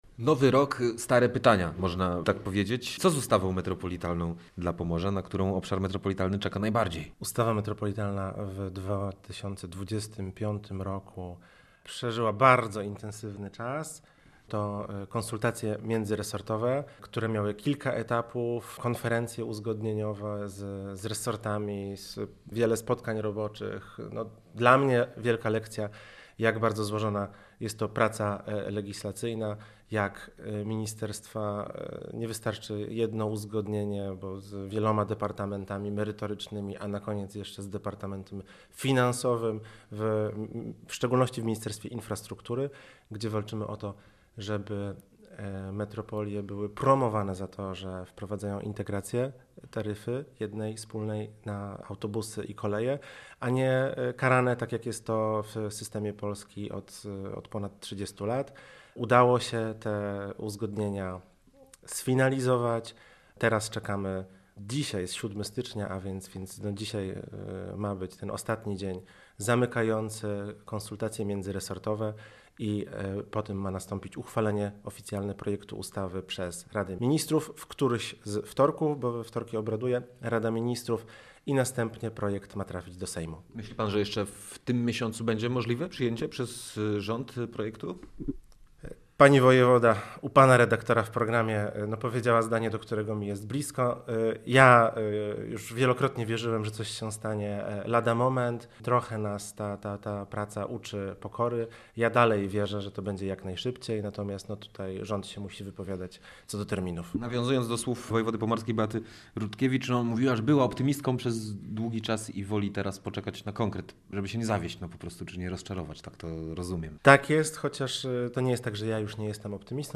W rozmowie poruszono również temat systemu roweru metropolitalnego Mevo.